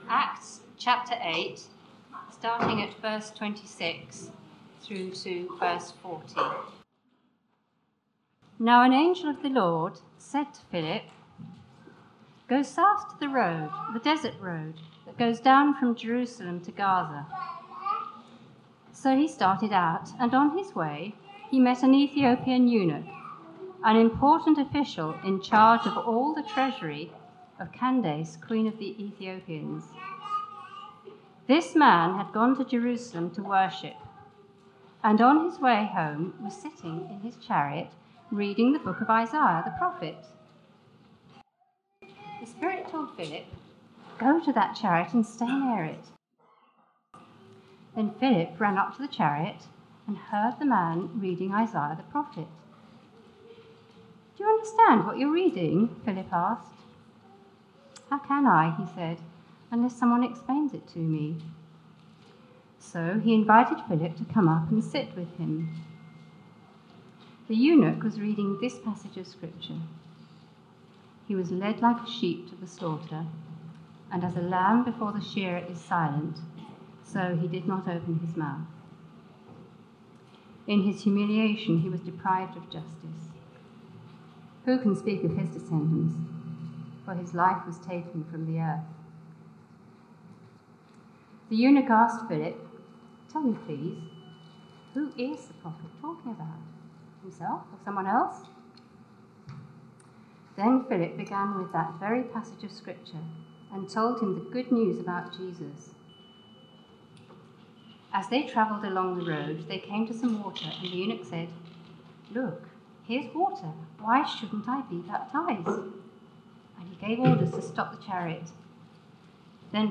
Theme: The church expands the boundaries to Africa Sermon